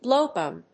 音節blów・gùn 発音記号・読み方
/ˈbloˌgʌn(米国英語), ˈbləʊˌgʌn(英国英語)/